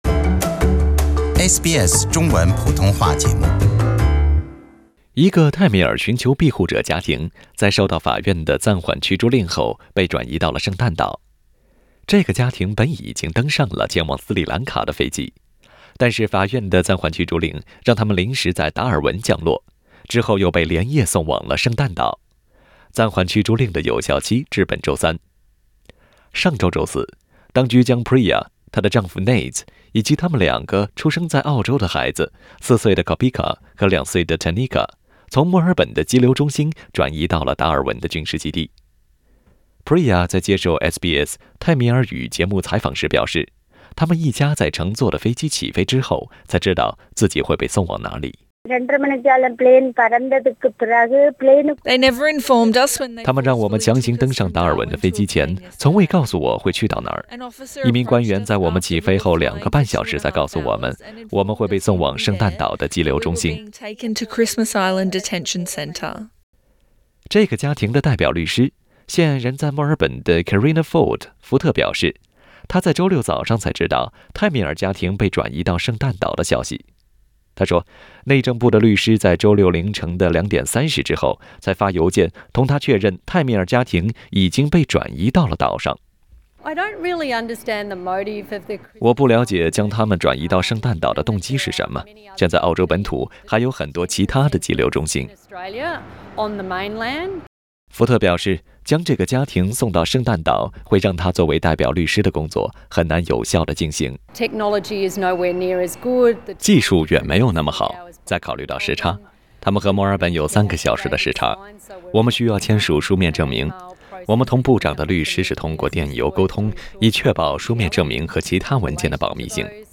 為您帶來詳細的報道。